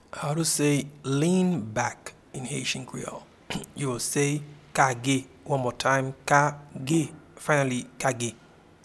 Pronunciation and Transcript:
Lean-back-in-Haitian-Creole-Kage.mp3